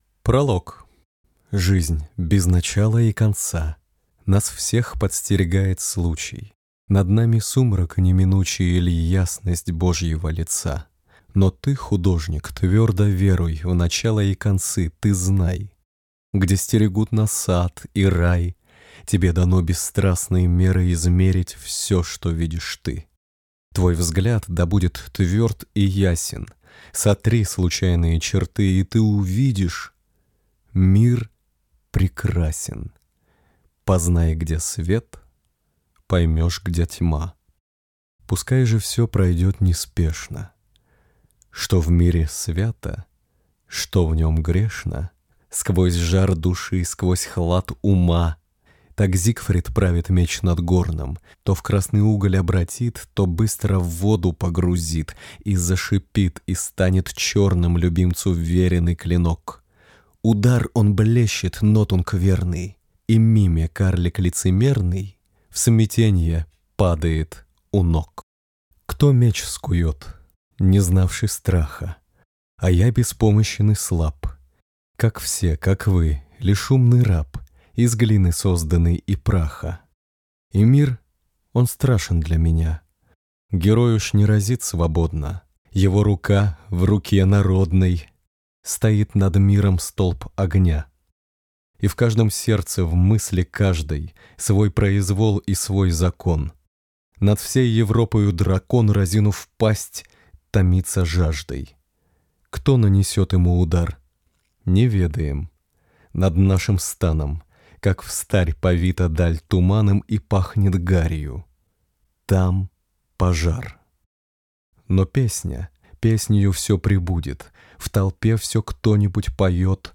Аудиокнига Возмездие | Библиотека аудиокниг